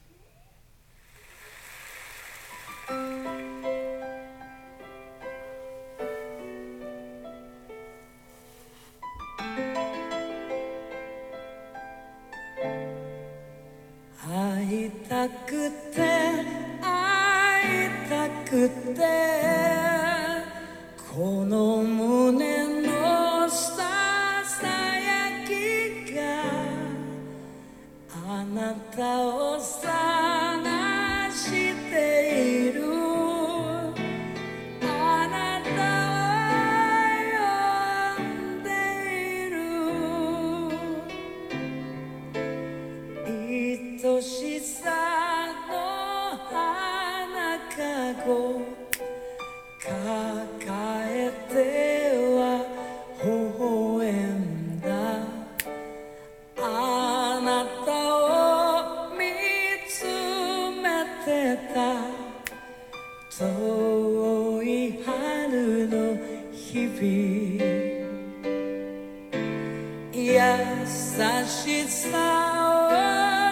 それとその証明をするため、わざと指を鳴らして環境音の録音をしてます。
ECM-PC60　がノイズが少なく扱いやすい印象です。
(Xperiaのイヤホン端子で出力しMacBook Proのスピーカーで出力し、それをマイクで録音)
ECM-PC60の録音結果　WAV　モノラル(LとRが同じ波形)　ご参考に。
(指鳴らし,指パッチン,フィンガースナップ)入り